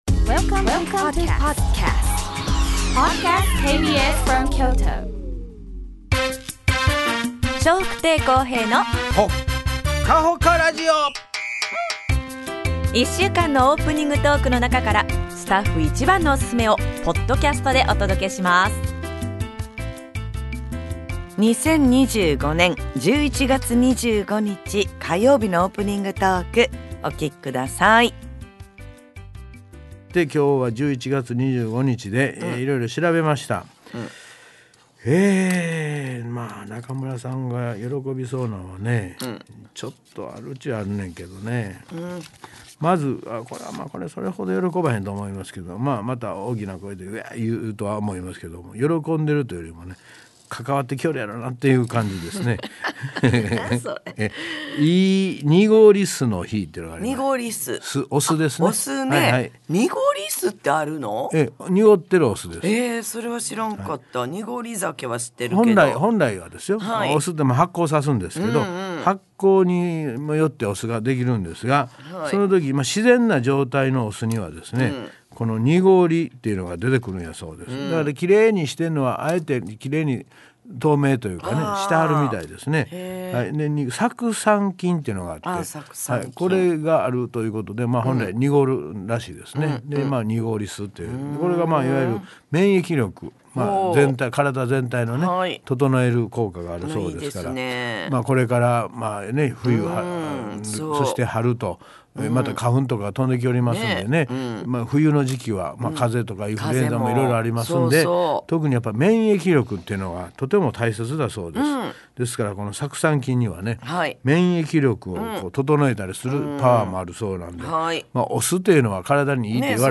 2025年11月25日のオープニングトーク